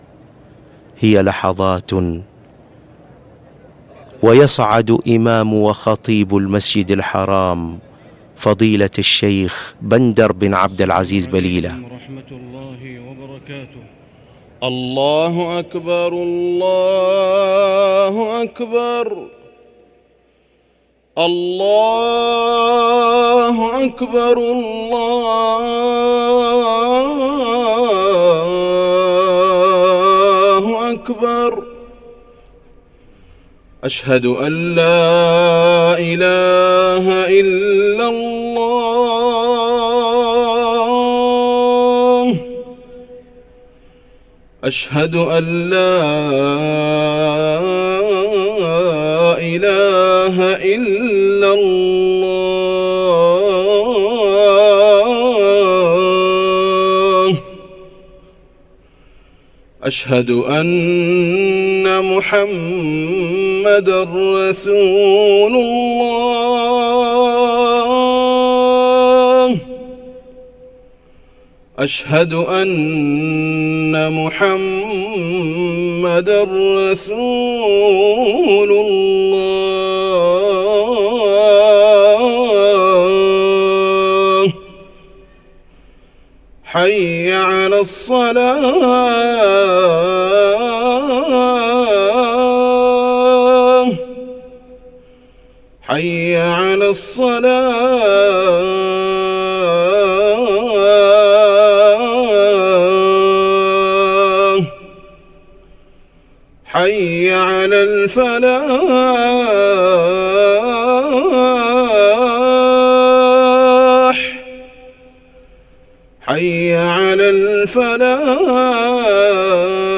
أذان الجمعة الثاني
ركن الأذان